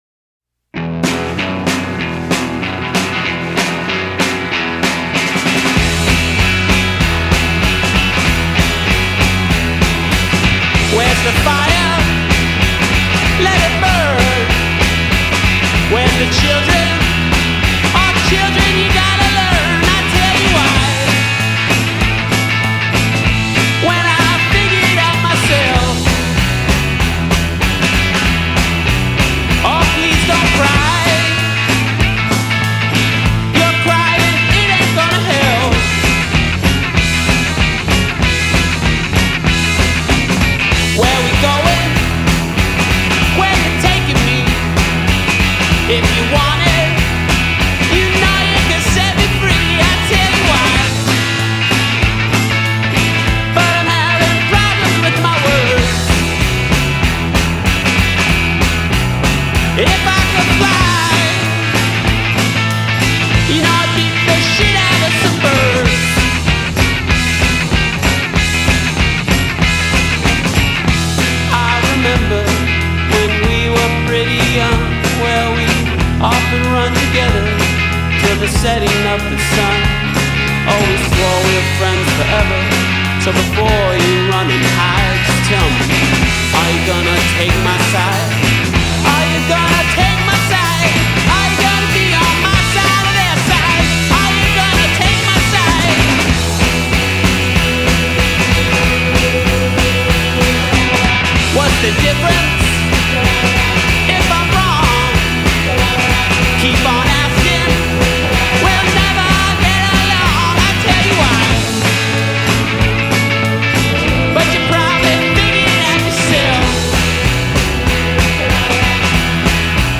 innerhalb einer woche im alten wohnzimmer
echten rock ’n‘ roll